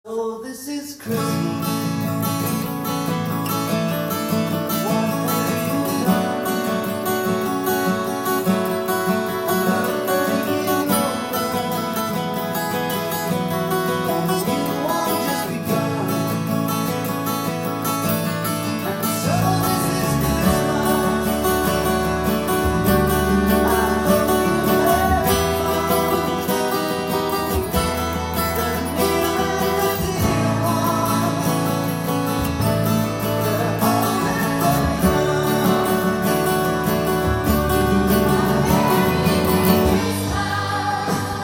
音源に合わせて譜面通り弾いてみました
2カポで弾くと譜面通りに弾くことが出来ます。
拍子が８分の６拍子というリズムになっているので